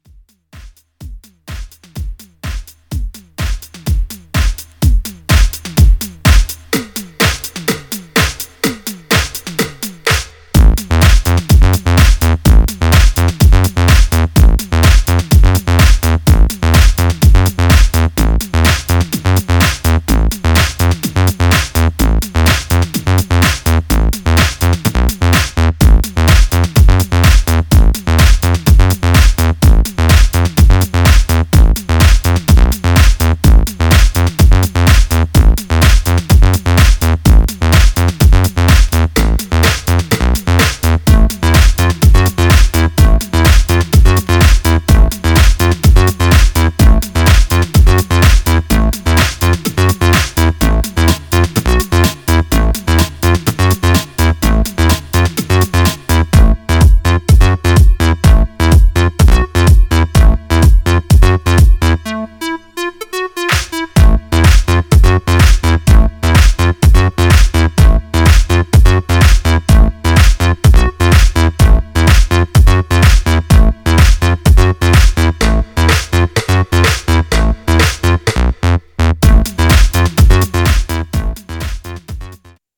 Styl: House